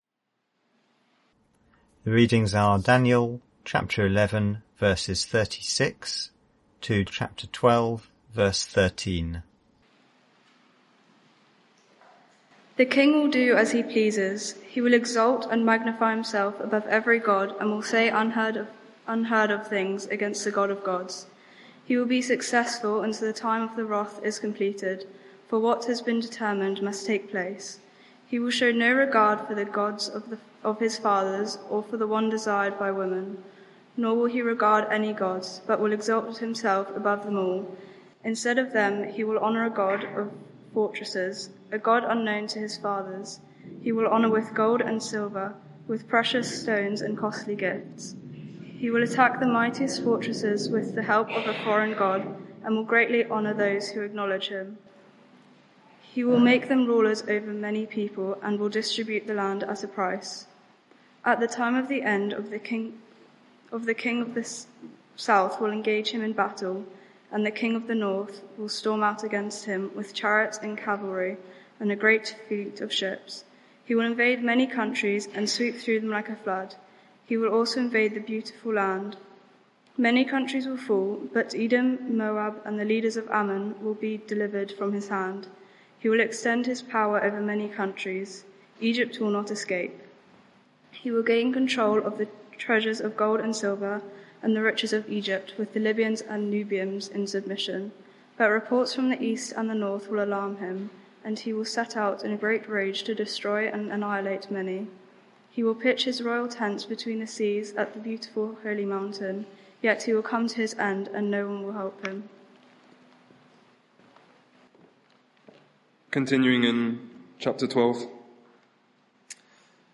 Media for 6:30pm Service on Sun 23rd Jul 2023 18:30 Speaker
Sermon (audio)